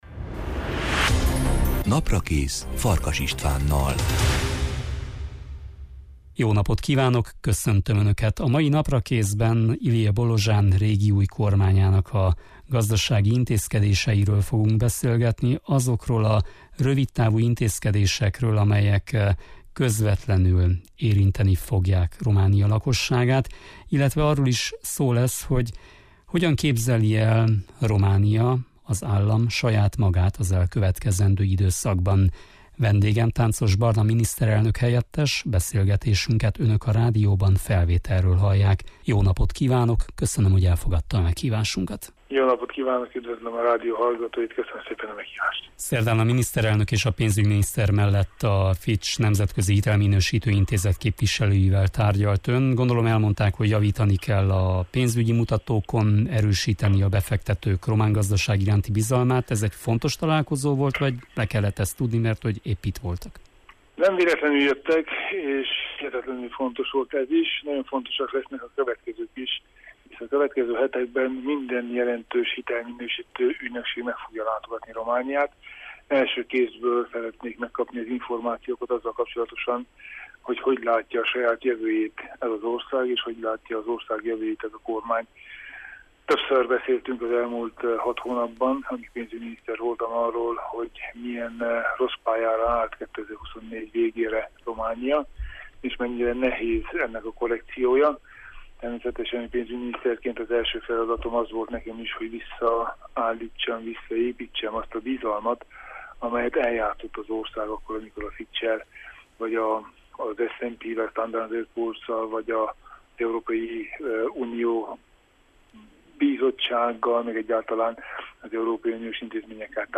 Ezek hátteréről és várható következményeiről beszélgetünk a mai Naprakészben Tánczos Barna miniszterelnök-helyettessel.